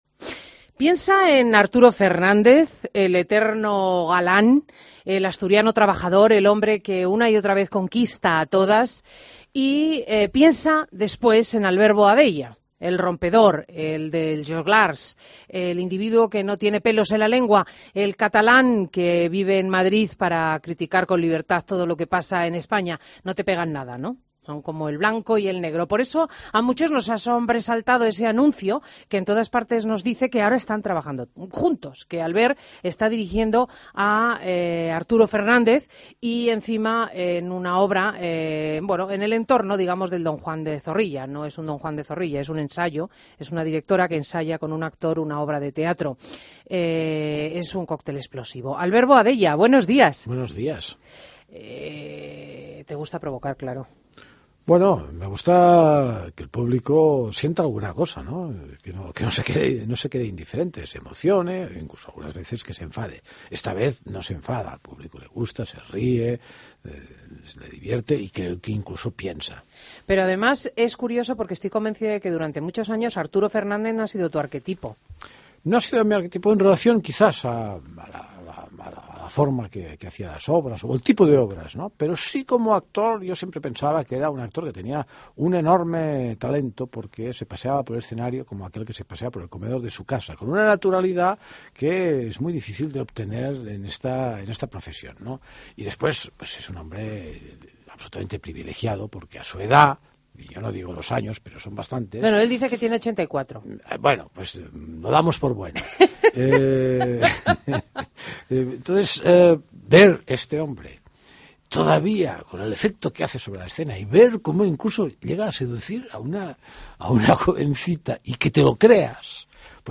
Entrevista a Albert Boadella y Arturo Fernández en Fin de Semana
AUDIO: Entrevista a Albert Boadella y Arturo Fernández en Fin de Semana